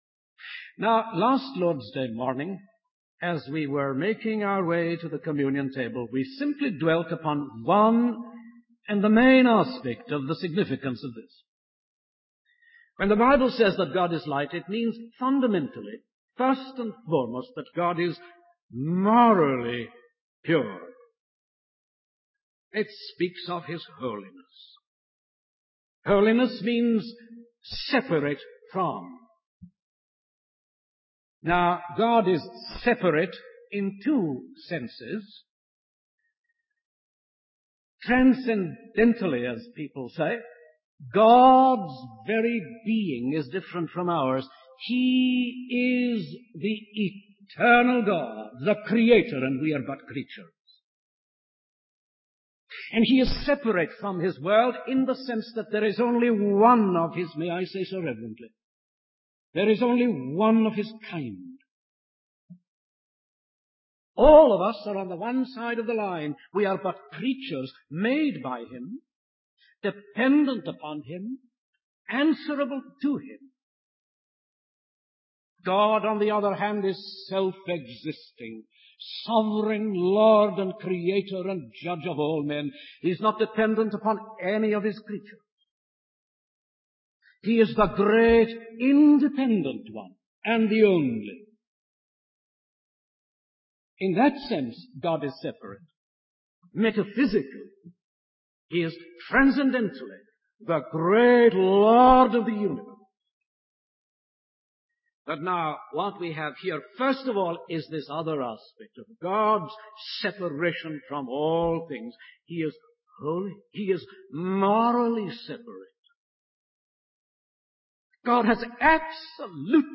In this sermon, the preacher emphasizes the importance of the message of Jesus Christ, which was taught by Him and received by the apostles.